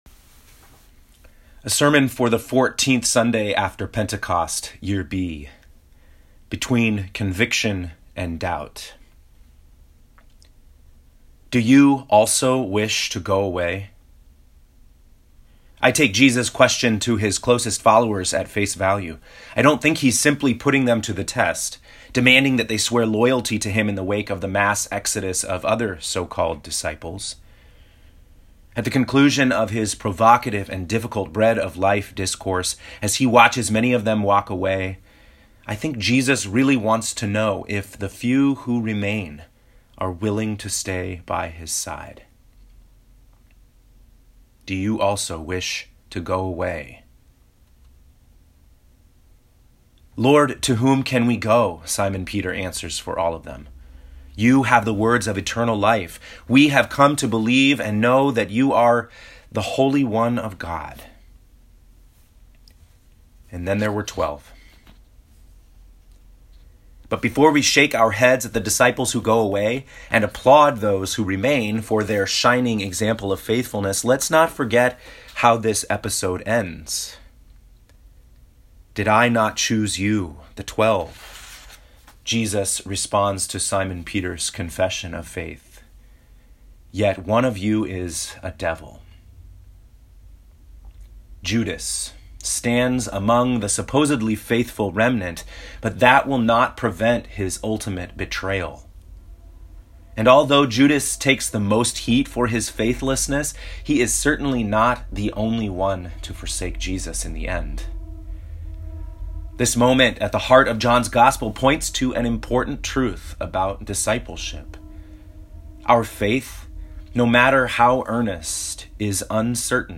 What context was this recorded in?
Fourteenth Sunday after Pentecost, Year B (8/26/2018)